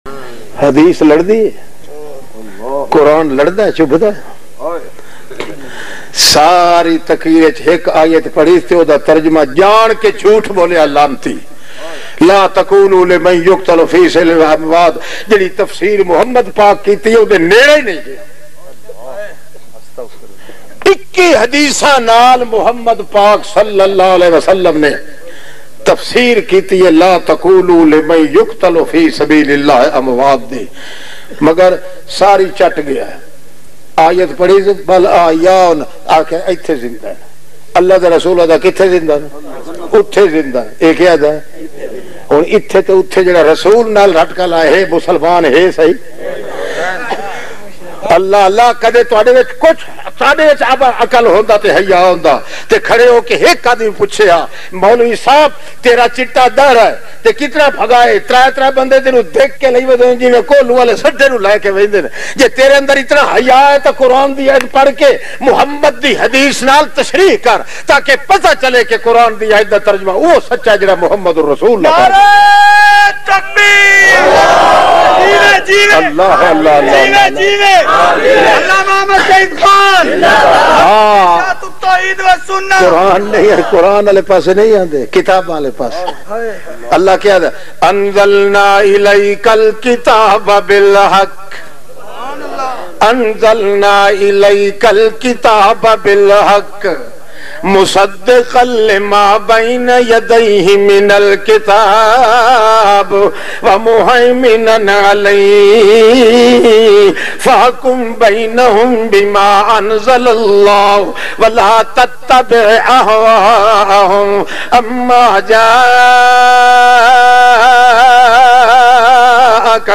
Husn E Mohammad bayan mp3